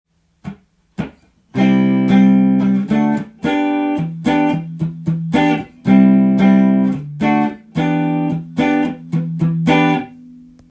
シンコペーションが入っていてカッコいい感じのコード進行ですね♪